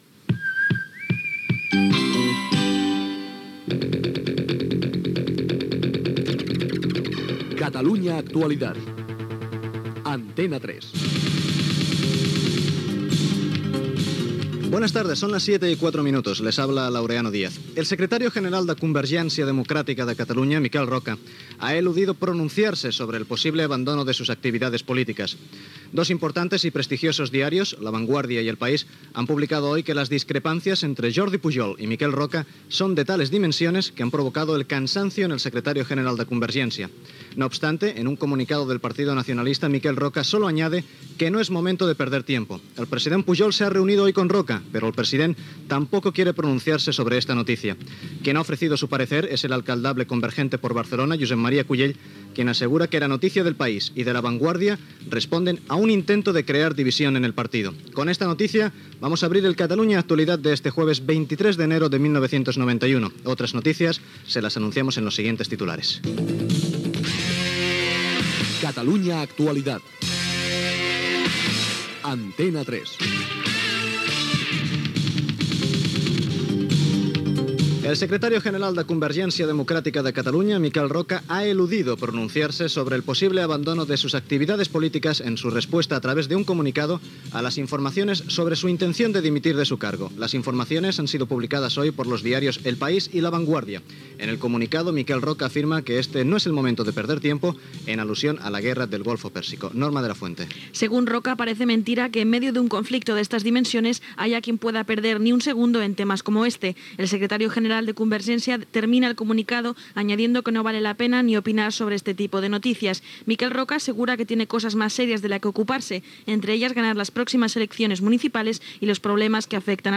Indicatiu del programa, possible abandonament de la política de Miquel Roca, data, indicatiu, sumari informatiu
Informatiu
FM